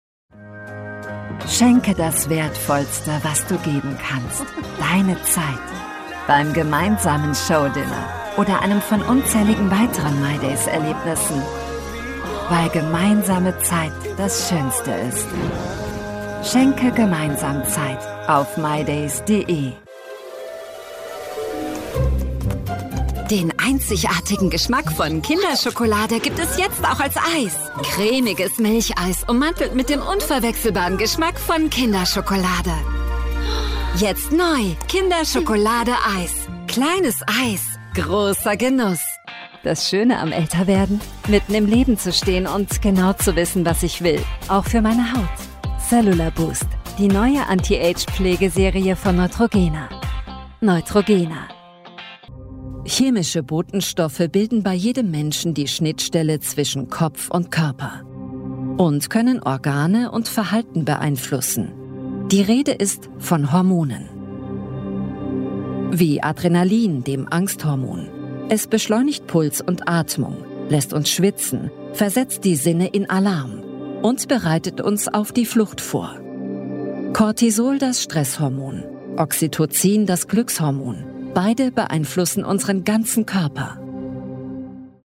Commercial Demo
• Mikrofon: Neumann TLM 67 / Neumann TLM 103
• Acoustic Cabin : Studiobricks ONE
ContraltoMezzo-Soprano
ConfidentDynamicExperiencedFriendlyReliableTrustworthyVersatileYoungWarm